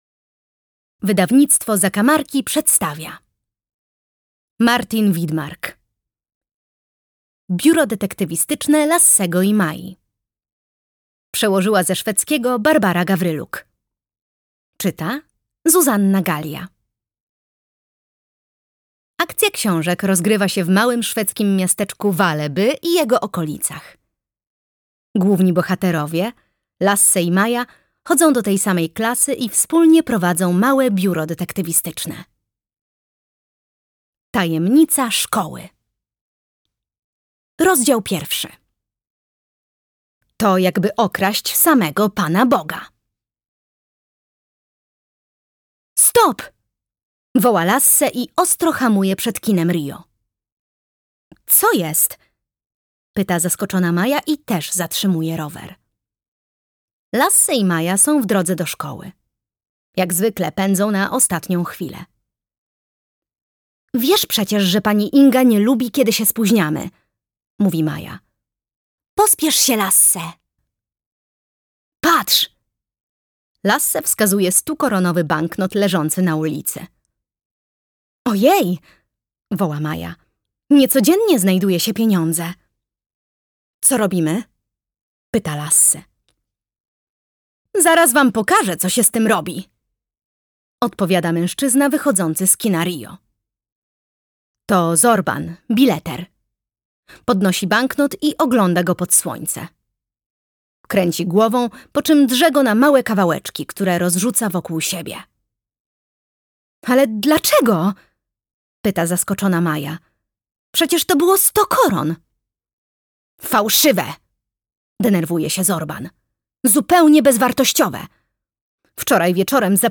Biuro Detektywistyczne Lassego i Mai. Tajemnica szkoły - Martin Widmark - audiobook